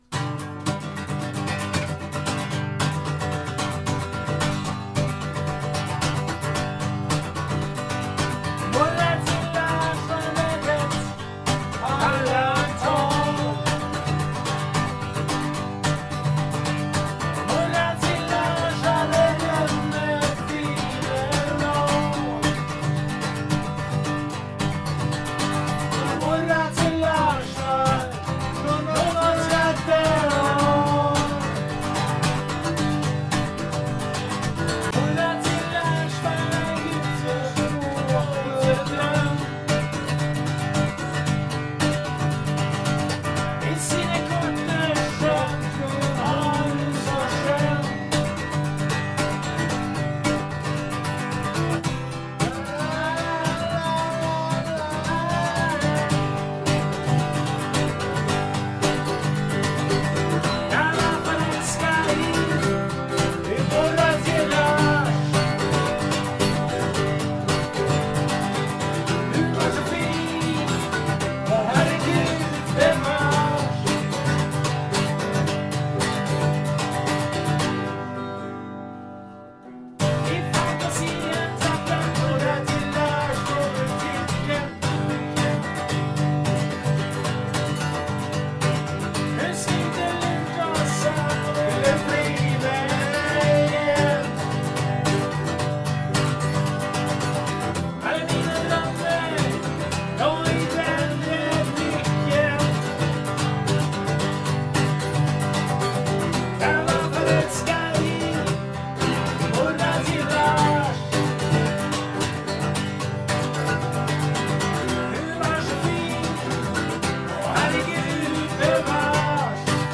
För säkerhets skull spelade vi in dessa övningar, som dessutom gav oss ett par helt nya låtar / idéer till låtar + andra versioner av gamla låtar.